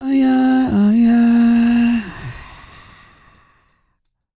E-CROON 3050.wav